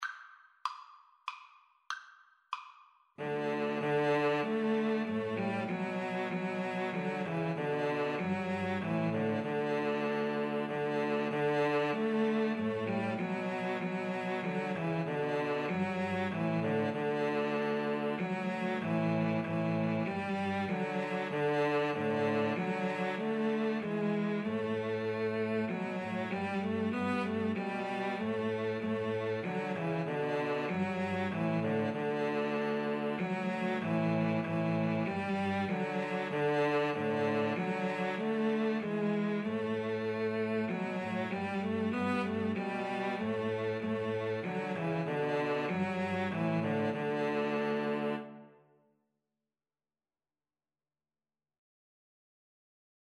Free Sheet music for Cello Trio
Cello 1Cello 2Cello 3
D major (Sounding Pitch) (View more D major Music for Cello Trio )
Andante expressivo = c. 96
3/4 (View more 3/4 Music)
Classical (View more Classical Cello Trio Music)